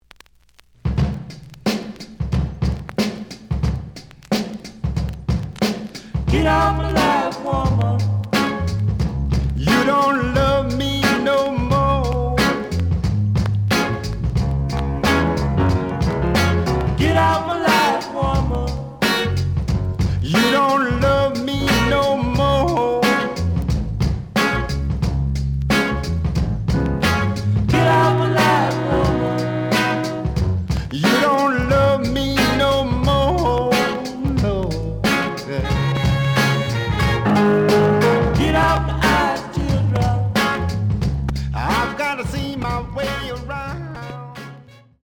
The audio sample is recorded from the actual item.
●Genre: Funk, 60's Funk
Some noticeable periodic noise on middle of A side.)